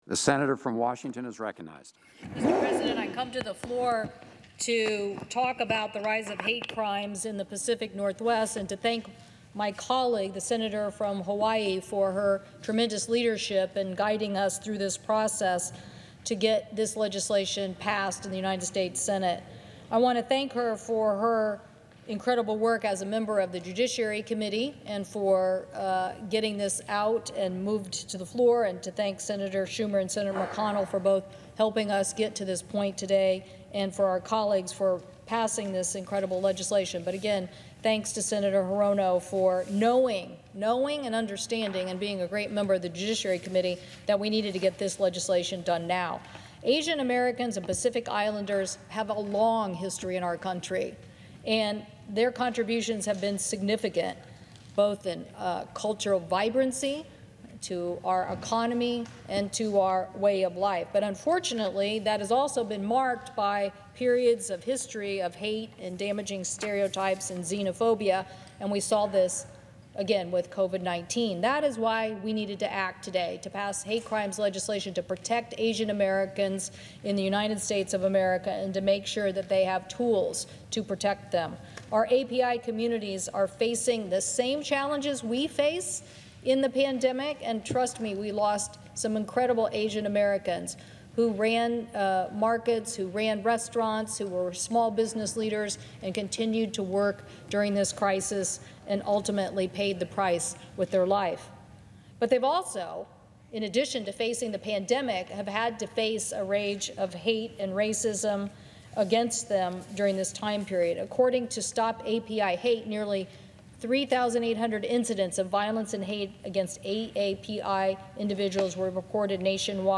Senate Passes AAPI Hate Crimes Legislation – Cantwell Speaks on Senate Floor Against Rise in AAPI Violence